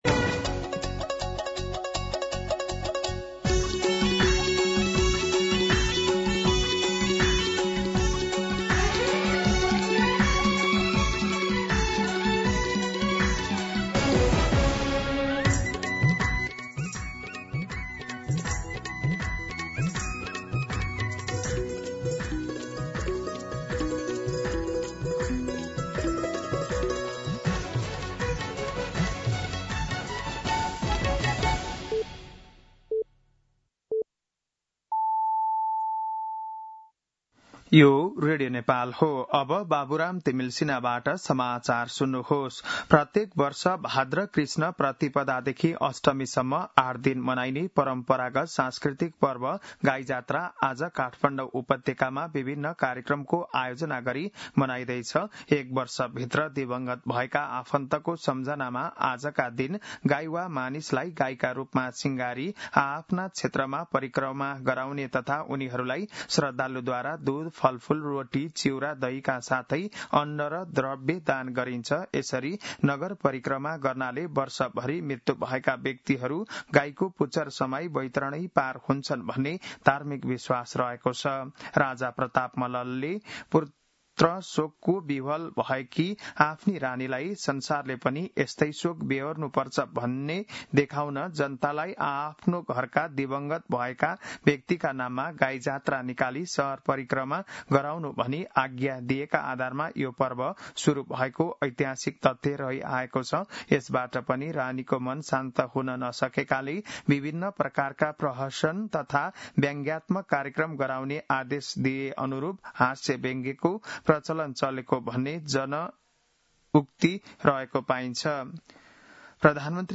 बिहान ११ बजेको नेपाली समाचार : २५ साउन , २०८२
11-am-Nepali-News-3.mp3